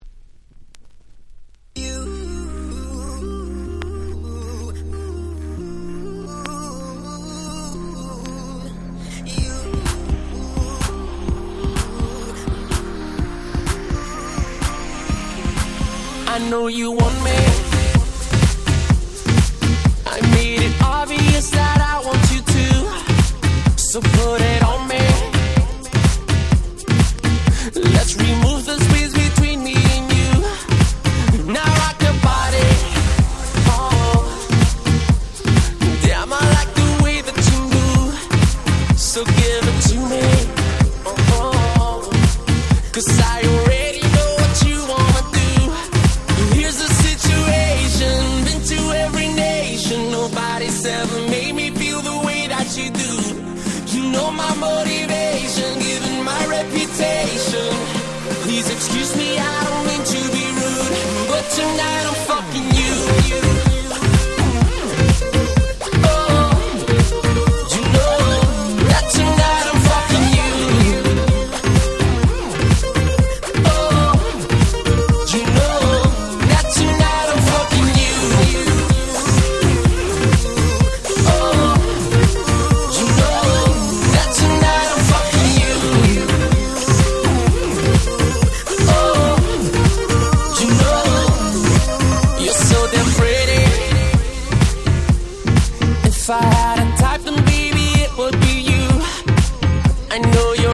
10' Super Hit R&B / Pops / Latin !!